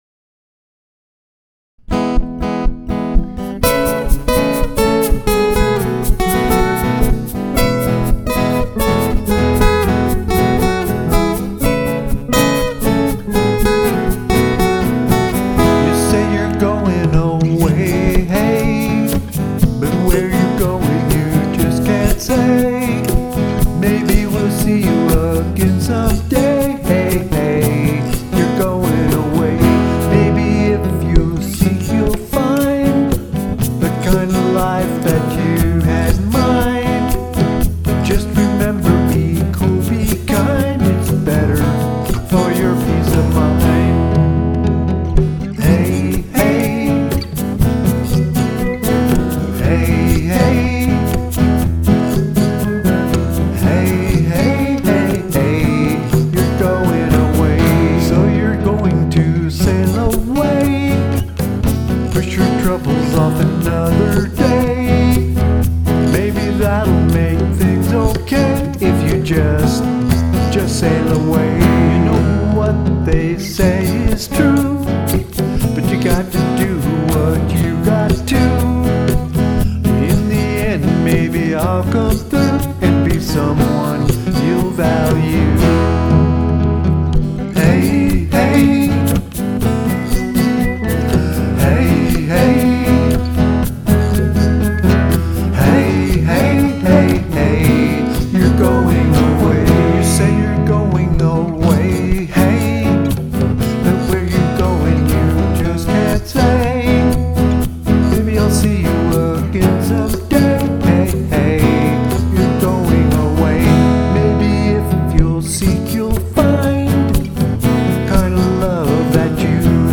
For the most part I played all of the instruments used during these recording  (acoustic guitar, electric guitar, bass guitar, piano, synthesizers and various percussion instruments) and sing most of the vocal parts in every song.
It is best to listen to these tunes with headphones as that is how I recorded and mixed them.